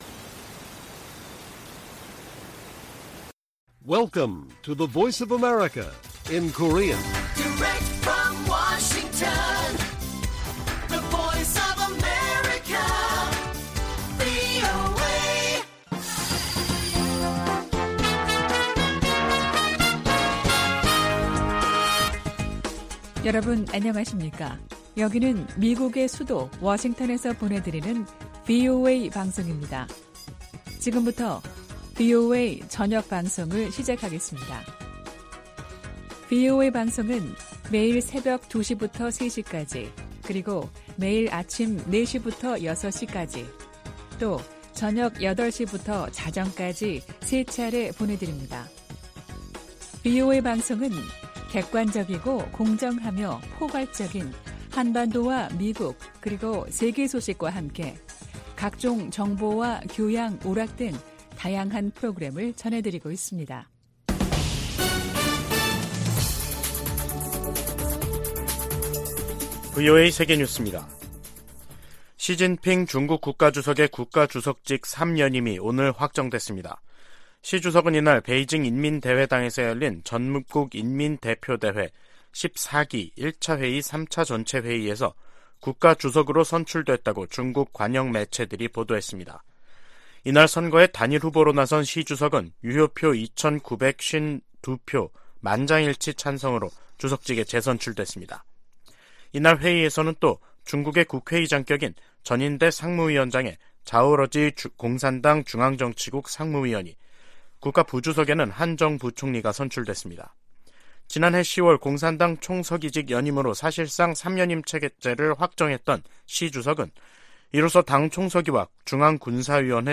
VOA 한국어 간판 뉴스 프로그램 '뉴스 투데이', 2023년 3월 10일 1부 방송입니다. 북한이 9일 신형 전술유도무기로 추정되는 탄도미사일을 최소 6발 서해로 발사했습니다. 미 국무부는 북한의 미사일 발사를 규탄하며, 대화에 열려 있지만 접근법을 바꾸지 않을 경우 더 큰 대가를 치르게 될 것이라고 경고했습니다. 미국 전략사령관이 의회 청문회에서 북한의 신형 대륙간탄도미사일로 안보 위협이 높아지고 있다고 말했습니다.